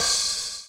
DUBHAT-23.wav